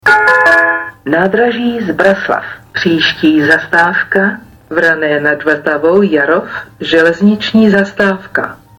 Vzhledem k tomu, že náhradní dopravu zajišťovaly autobusy Dopraního podniku hlavního města Prahy, ve všech spojích (včetně toho do Čerčan) byly vyhlašovány zastávky standardním způsobem.
- Hlášení "Nádraží Zbraslav. Příští zastávka Vrané nad Vltavou, Jarov, železniční zastávka" si